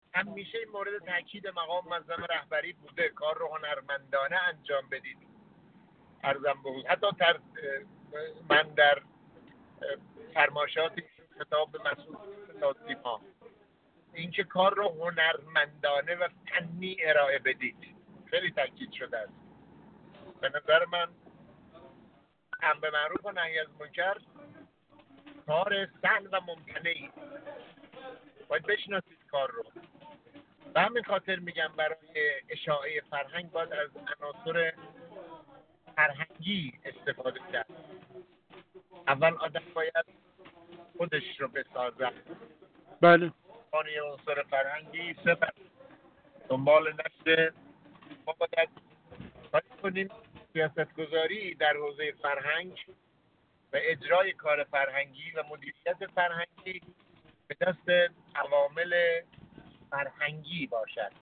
محمود عباس‌زاده مشکینی، عضو کمیسیون امنیت ملی و سیاست خارجی مجلس، در گفت‌وگو با ایکنا درباره الزامات حکمرانی فرهنگی و اجزای مهم مدیریت فرهنگی گفت: ظریف‌ترین، حساس‌ترین، تخصصی‌ترین و استراتژیک‌ترین وظیفه دولت‌ها در حوزه فرهنگ است و این حوزه حتی از اقتصاد و سیاست نیز ظریف‌تر است ولذا مدیریت فرهنگی کار هر کسی نیست و نیازمند مدیری با ویژگی‌های خاص است.